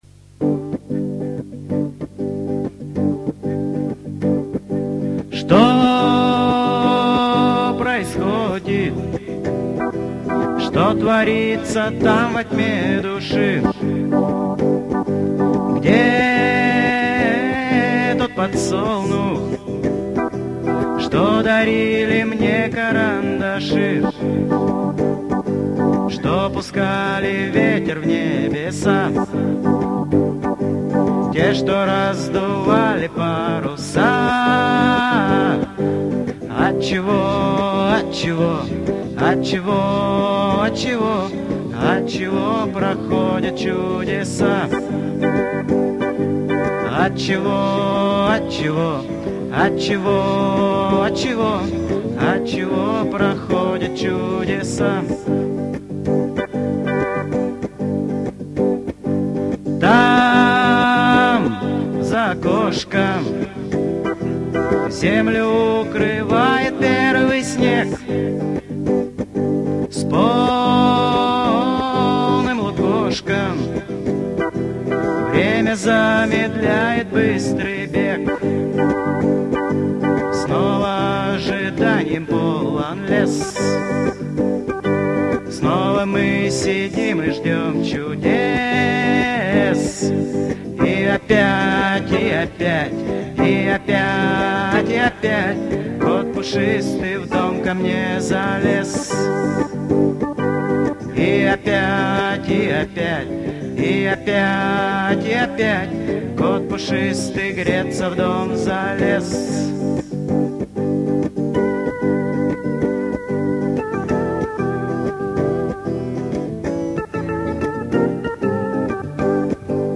Все песни исполняет автор